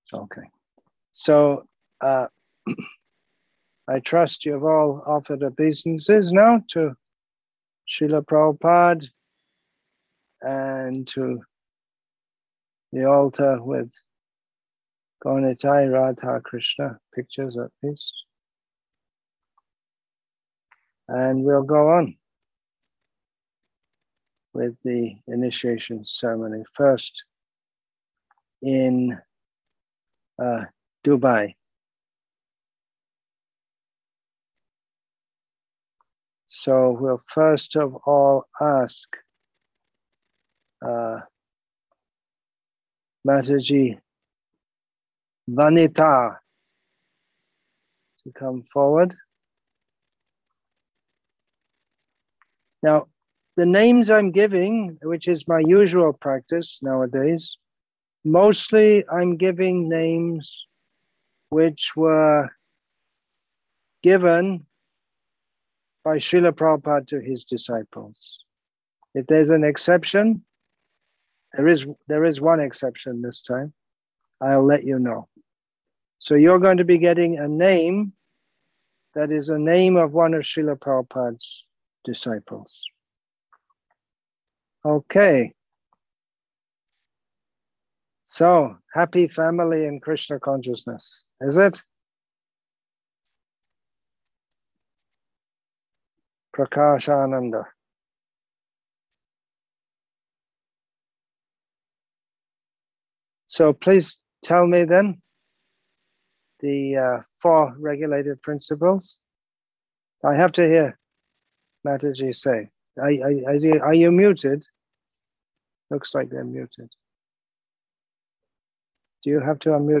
Online Initiation Ceremony and Conversation With Disciples